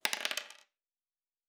pgs/Assets/Audio/Fantasy Interface Sounds/Dice Single 1.wav at master
Dice Single 1.wav